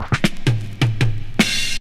23DR.BREAK.wav